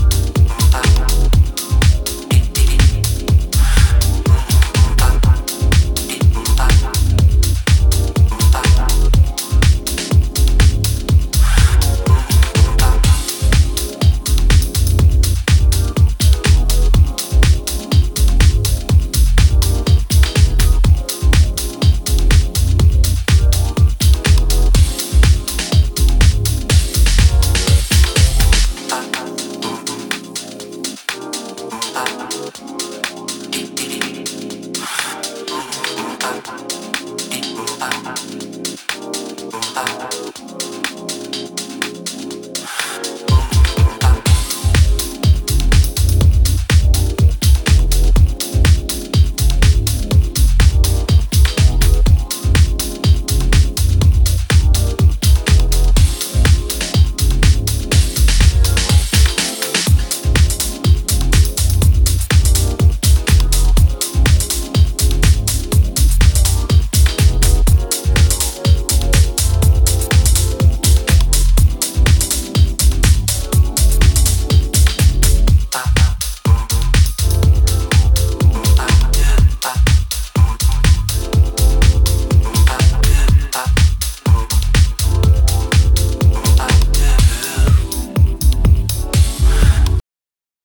モダンなセンスのディープ・ハウス群を展開。じっくりうねるビートダウン・ハウス